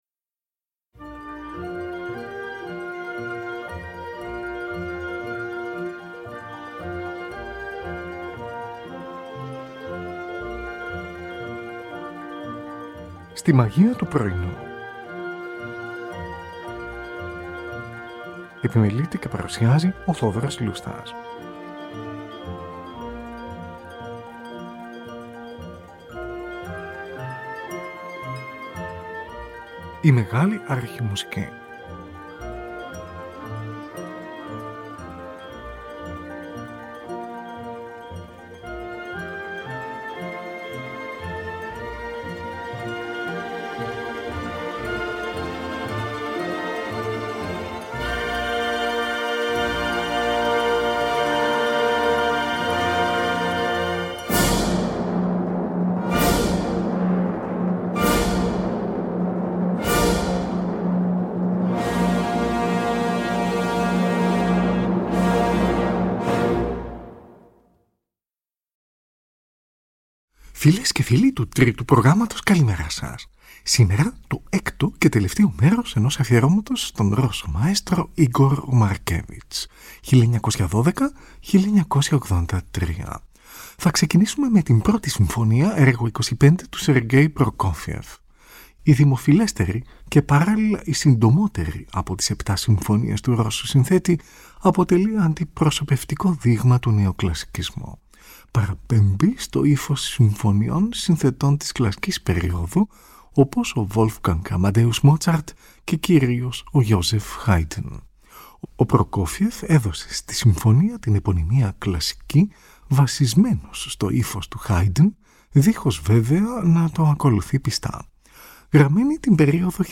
Ορχηστρική Σουίτα